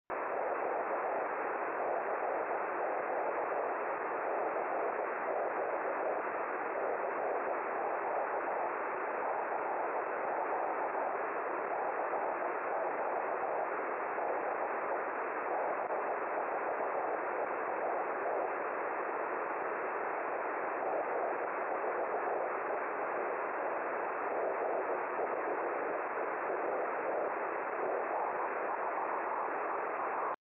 à typical CW sound: